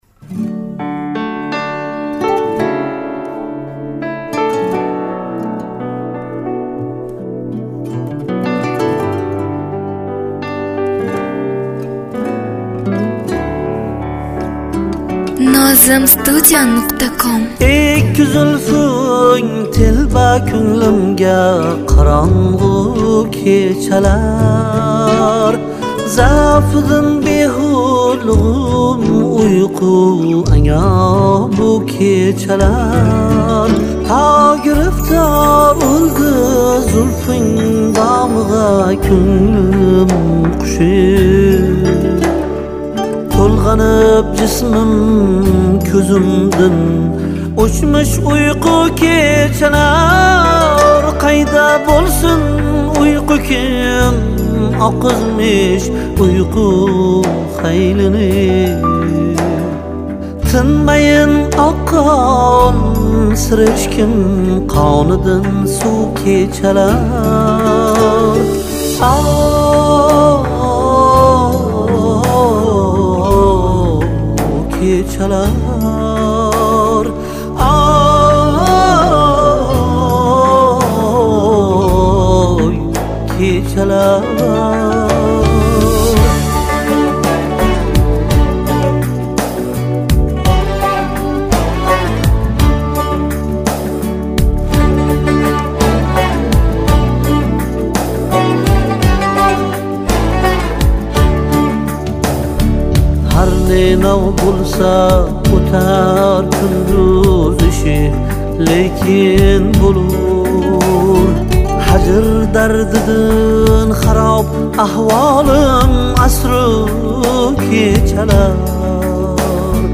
minus